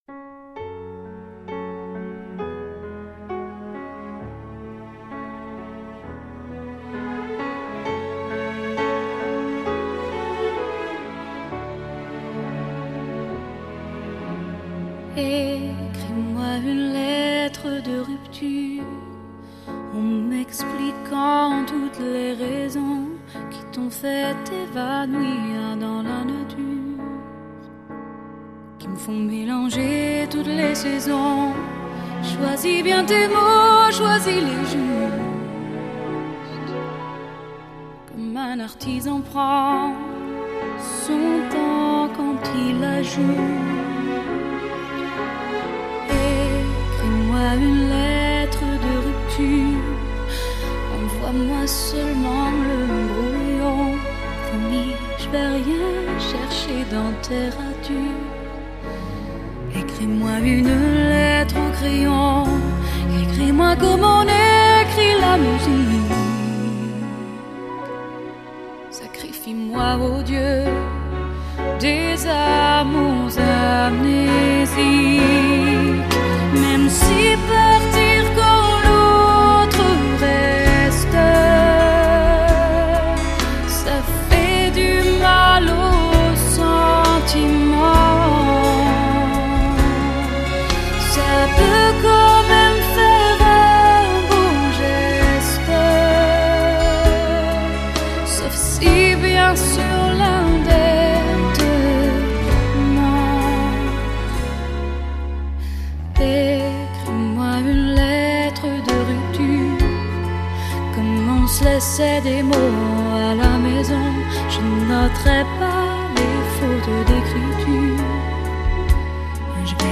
抒情单曲